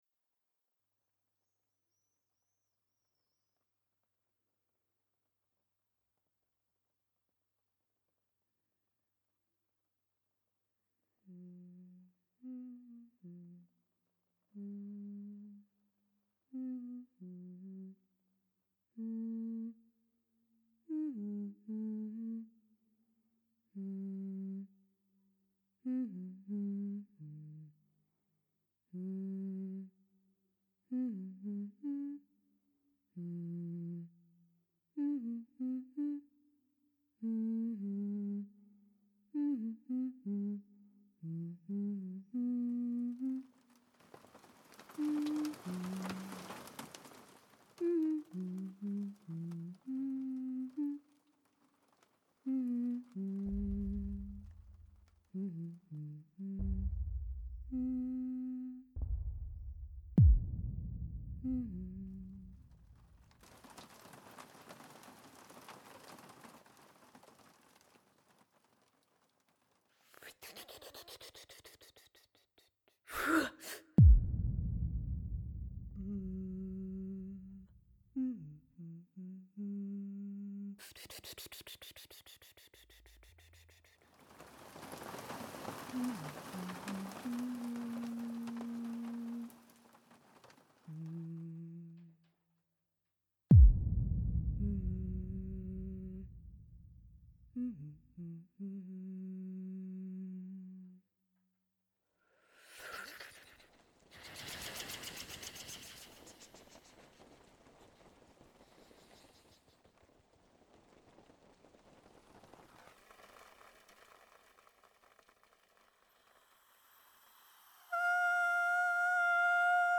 Improvisation acousmatique et vocale
Le geste musical est perceptible ; il y a le bois, le muscle, les pleins, les creux, la peau, les os… Le corps est un instrument aussi, qui trace sa propre cartographie entre les lignes de la musique en fabrication ; respirations, déplacements, mouvements…
3 Improvisations enregistrées en septembre 2012 et en mai 2013 au GMEM à Marseille.